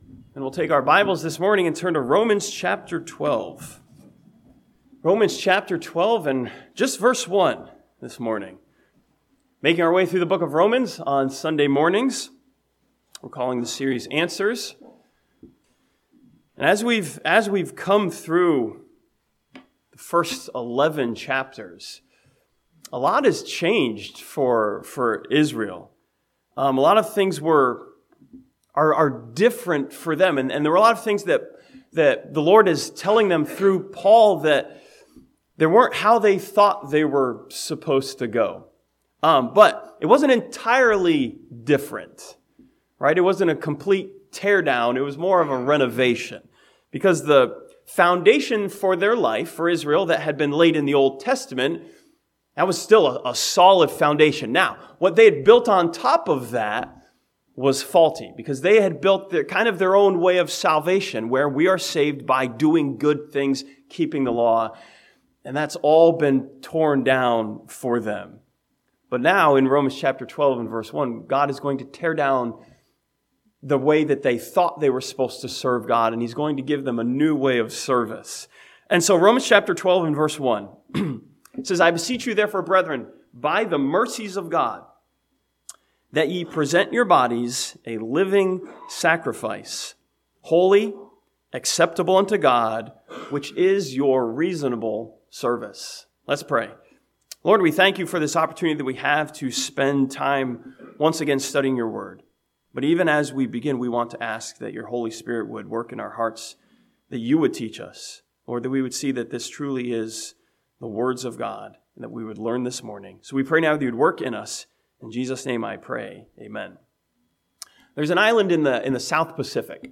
This sermon from Romans chapter 12 verse 1 asks a question of change, "How do we serve God now?"